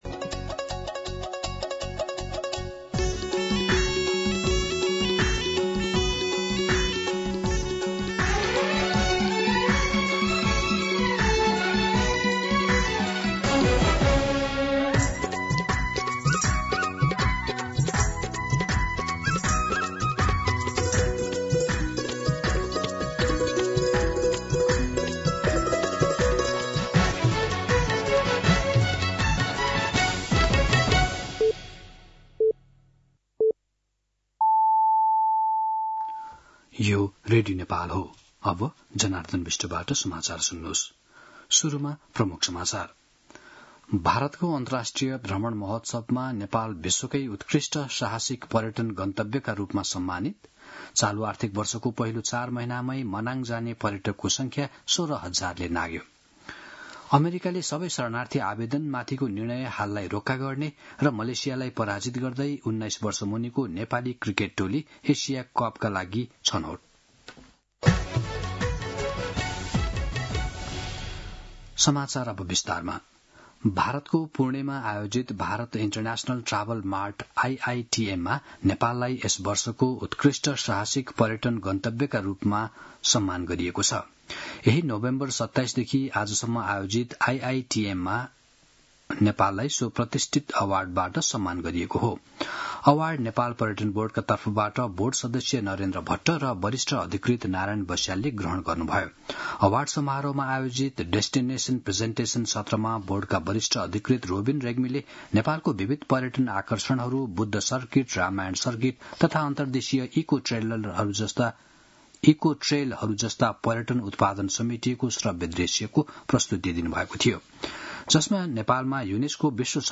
दिउँसो ३ बजेको नेपाली समाचार : १३ मंसिर , २०८२